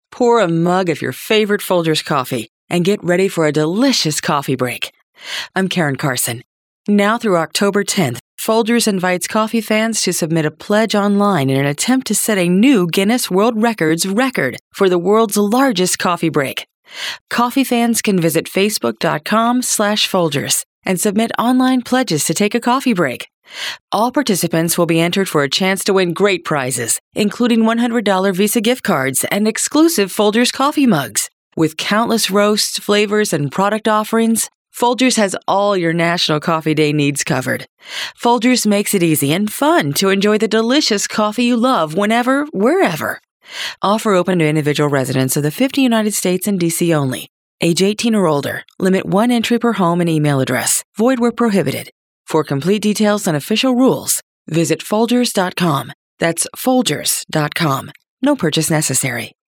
September 26, 2013Posted in: Audio News Release